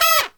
FALL HIT01-L.wav